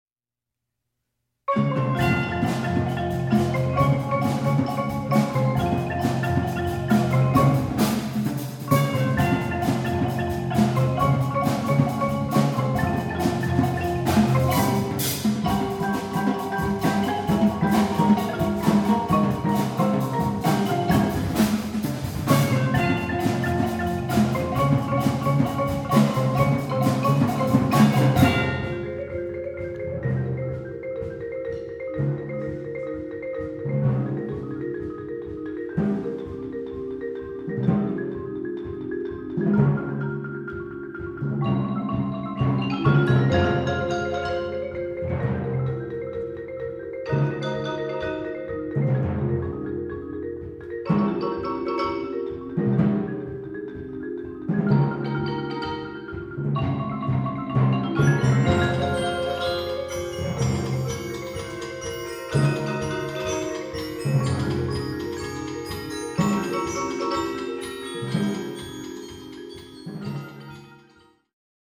Genre: Percussion Ensemble
Chimes/Bells
Xylophone
Vibraphone
Timpani
Percussion 1 (large cowbell, triangle, temple blocks)
Percussion 3 (4 concert toms)
Percussion 4 (drum set, vibraphone—optional)